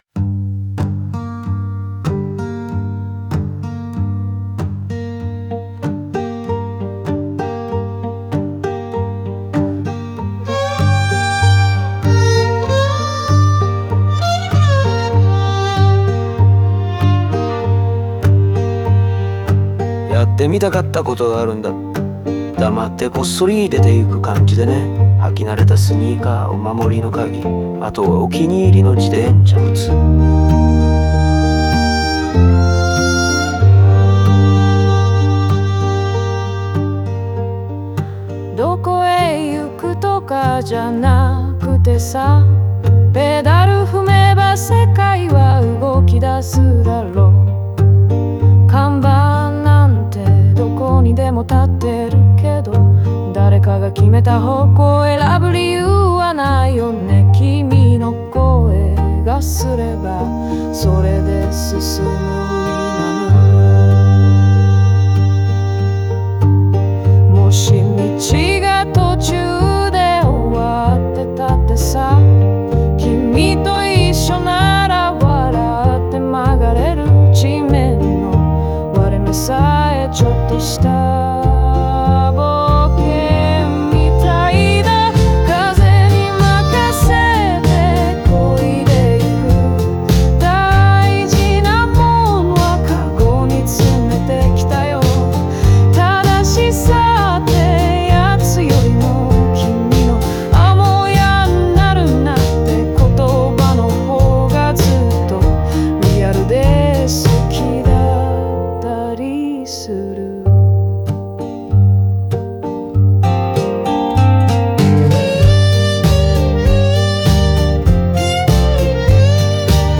オリジナル曲♪
語りかけるような語調と軽快なリズムは、肩の力を抜いた自由な旅の感覚を与え、聴く者に共感と安心感をもたらす。